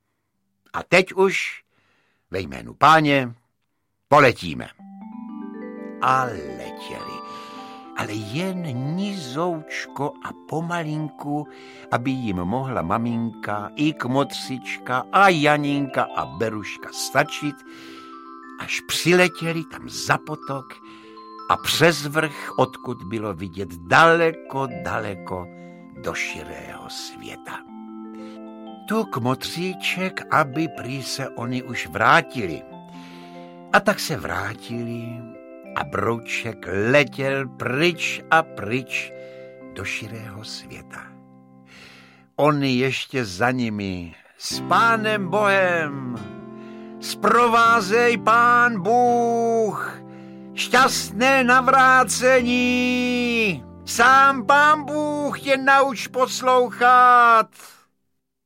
Broučci audiokniha
Jedna z nejpopulárnějších dětských knížek v laskavém podání Josefa Somra!
Ukázka z knihy